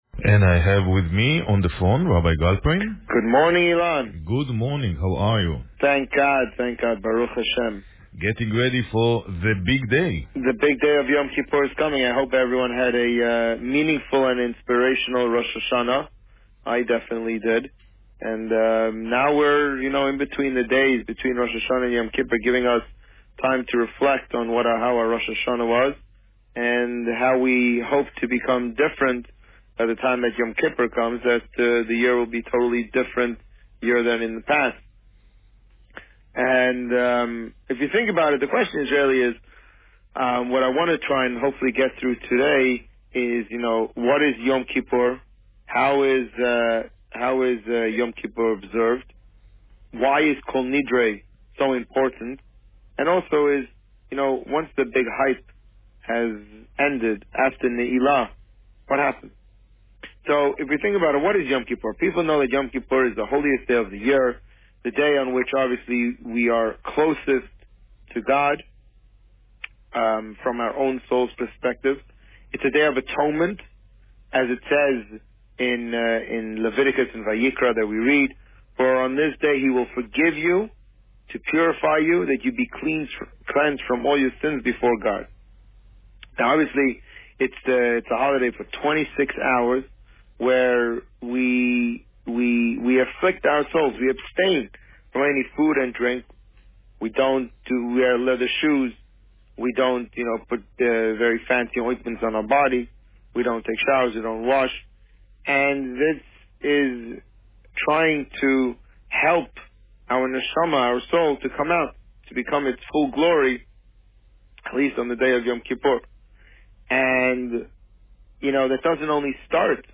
Today, the rabbi spoke about the torah portion Vayelech and preparations for the Yom Kippur service. Listen to the interview here.